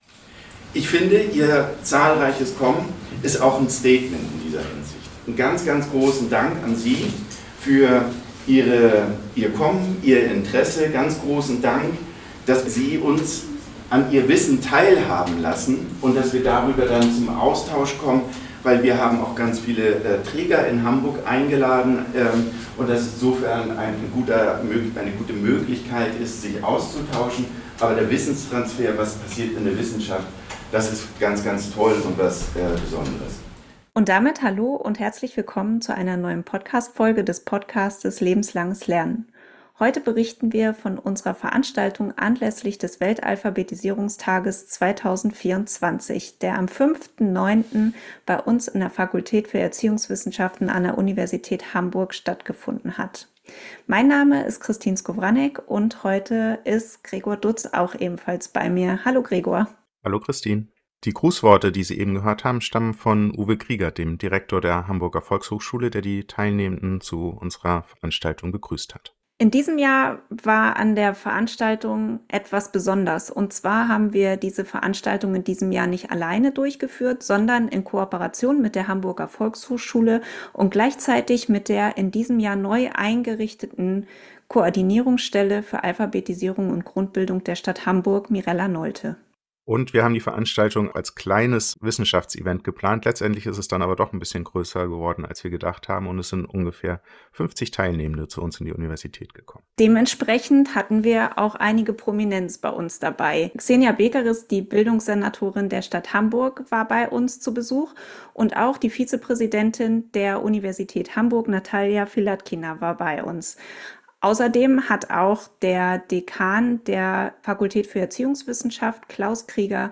In dieser Ausgabe des Podcasts „Lebenslanges Lernen“ haben wir Ausschnitte aus Vorträgen vorbereitet, die anlässlich des Welt-Alphabetisierungs-Tags 2024 auf einer gemeinsamen Veranstaltung von Universität Hamburg und Hamburger Volkshochschule präsentiert wurden.
Bei diesem kleinen Wissenschaftsevent wurden neue Forschungsergebnisse zur Alphabetisierung präsentiert. Die Beiträge umfassten ein vielfältiges thematisches Spektrum: Sie reichten von künstlicher Intelligenz und finanzieller Grundbildung über Literalität und Schwangerschaft bis hin zur Dokumentation von Grundbildungsfähigkeiten sowie intersektionalen Perspektiven in der Literalitätsforschung und boten somit einen umfassenden Einblick in die Forschungslandschaft.